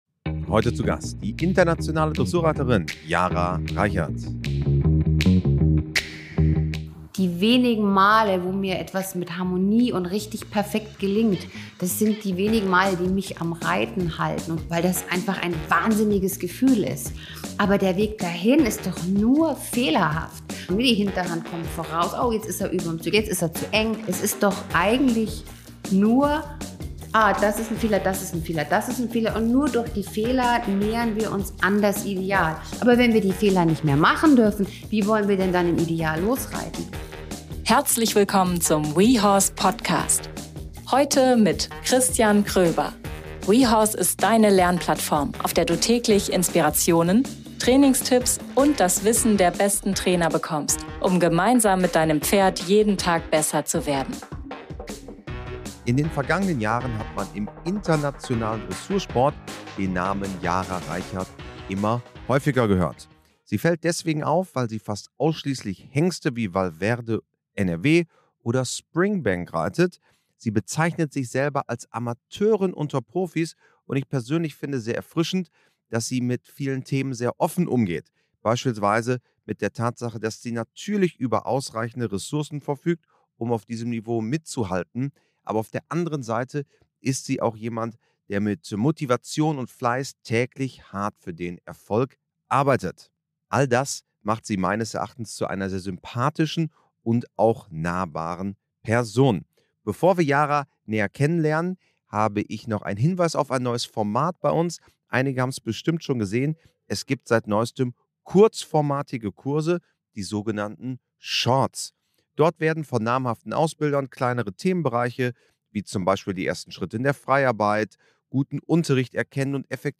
So erlebst du insgesamt ein Gespräch mit viel Ehrlichkeit und wertvollen Denkanstößen, das zeigt: Hinter jedem Erfolg stecken Mut, Vertrauen und Pferde, die ihre Menschen jeden Tag neu erden.